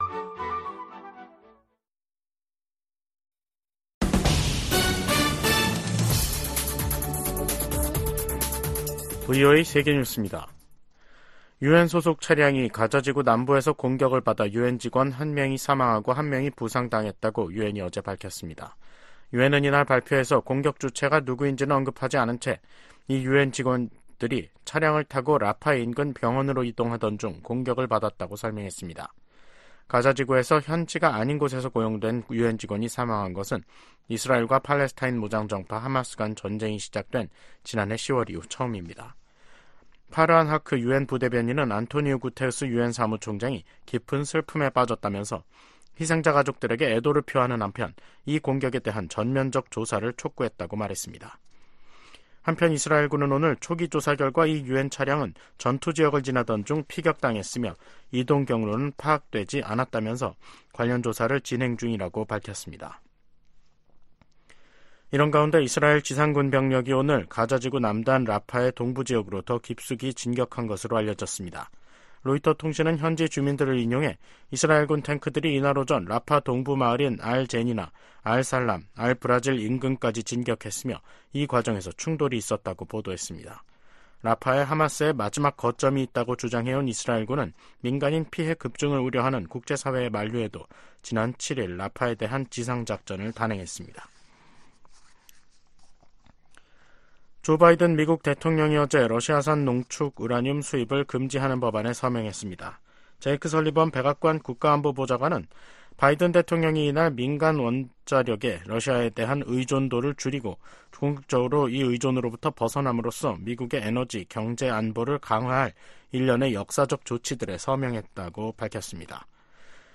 VOA 한국어 간판 뉴스 프로그램 '뉴스 투데이', 2024년 5월 14일 2부 방송입니다. 러시아가 철도를 이용해 북한에 유류를 수출하고 있다는 민간 기관 분석이 나온 가운데 실제로 북러 접경 지역에서 최근 열차 통행이 급증한 것으로 나타났습니다. 러시아가 올해 철도를 통해 25만 배럴의 정제유를 북한에 수출했다는 분석이 나온 데 대해 국무부가 북러 협력 심화에 대한 심각한 우려를 나타냈습니다.